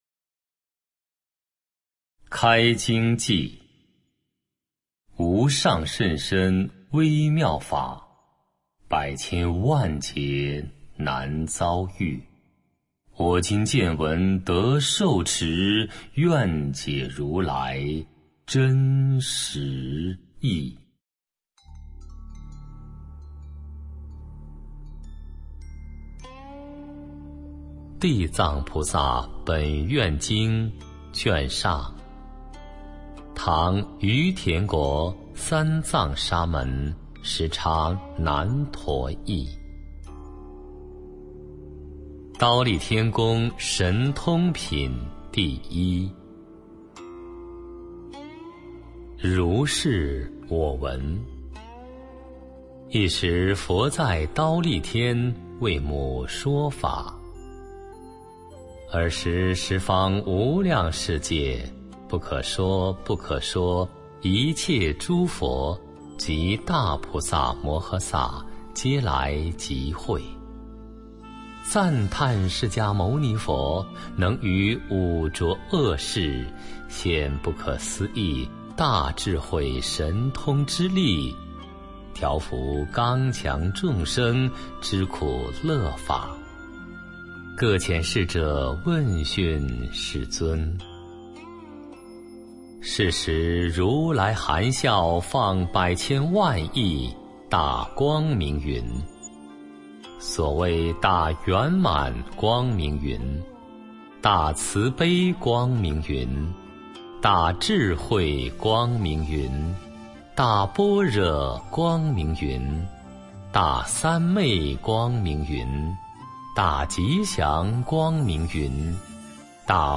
地藏经读诵（本地音频） - 佛乐诵读